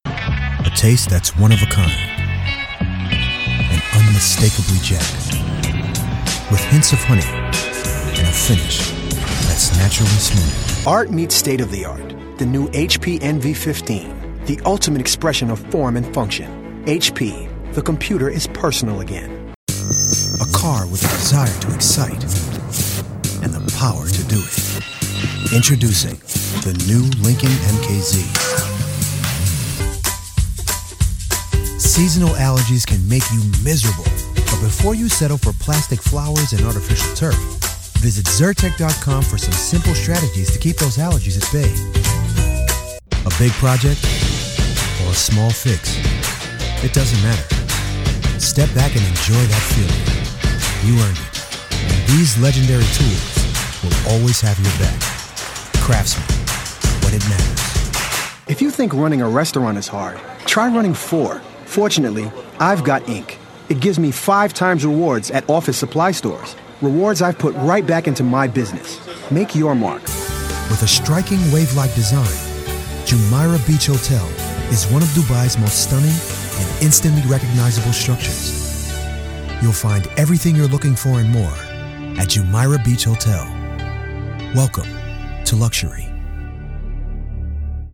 Demo
Young Adult, Adult
Has Own Studio
african american | natural
british rp | natural
southern us | natural
standard us | natural